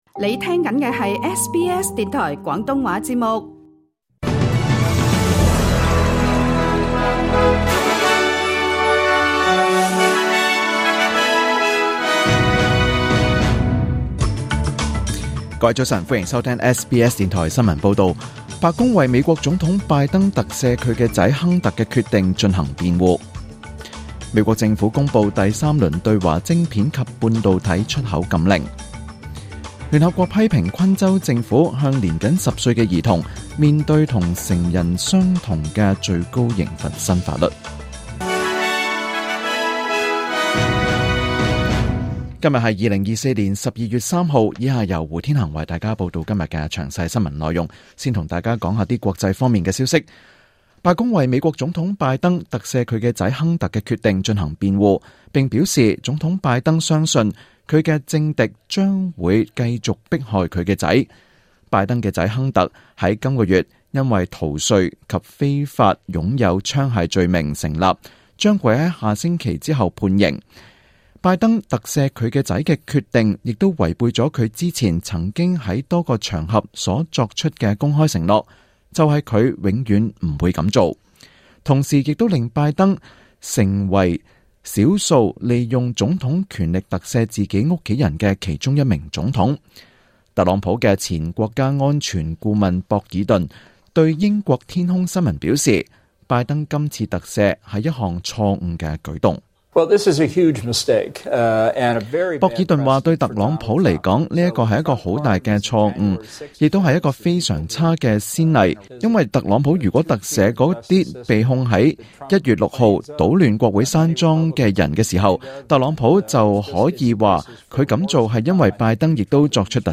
2024年12月3日SBS廣東話節目詳盡早晨新聞報道。